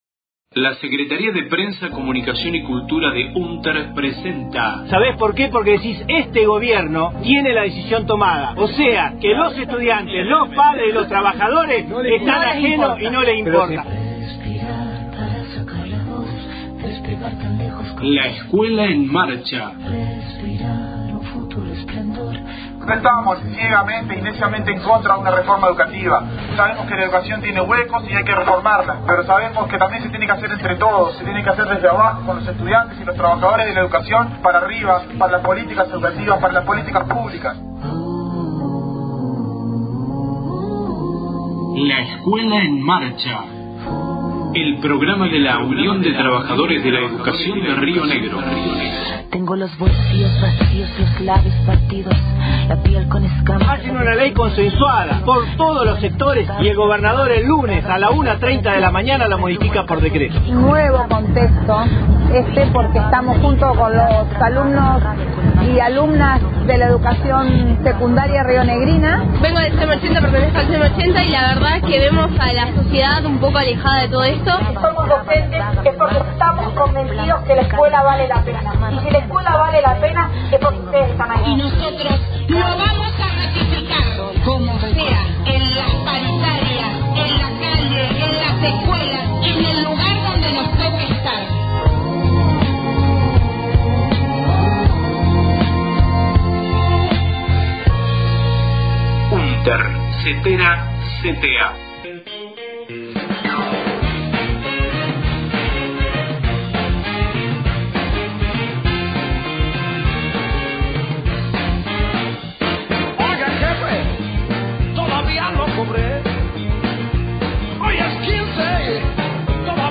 Audio Conferencia de prensa, centrales sindicales convocan a paro el 25/06/18, contra el ajuste, los tarifazos y el FMI